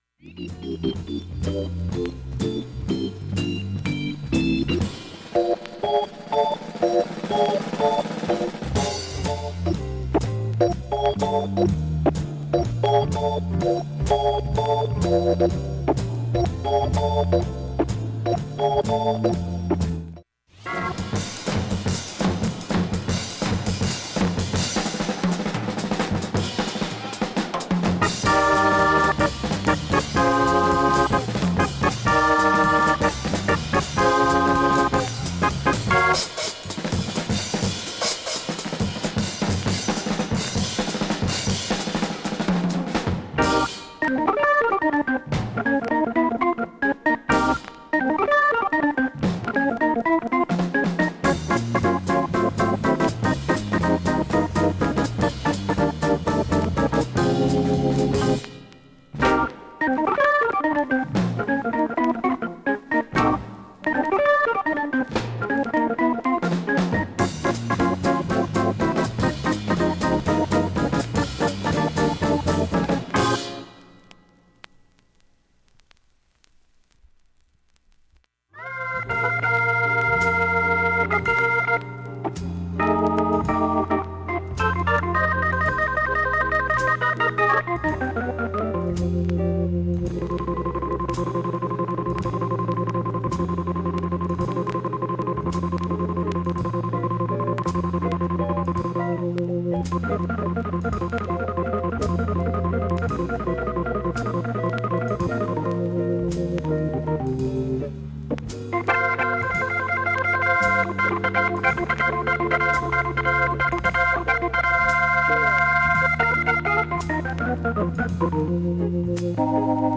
基本クリアないい音質です。
音質良好全曲試聴済み。
３回までのかすかなプツが５箇所
単発のかすかなプツが６箇所
ファンキーなオルガン、
トリオソウルジャズ